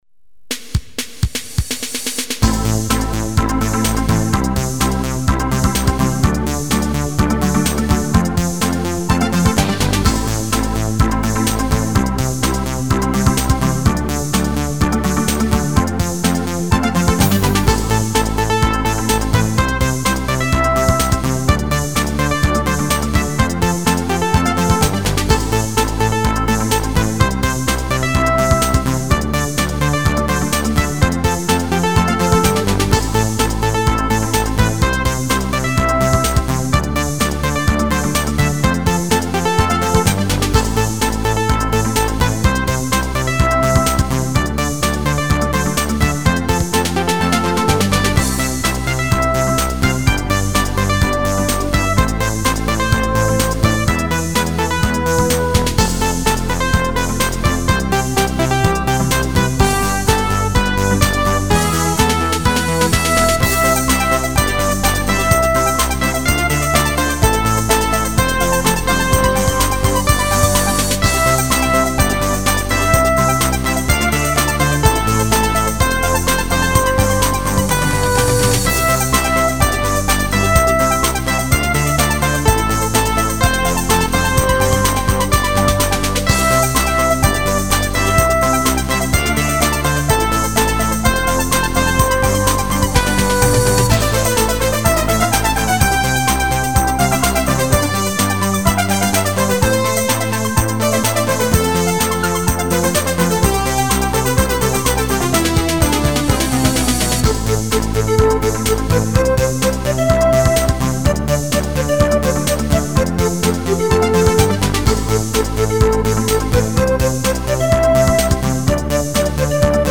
©1994 - Simpatico brano disco.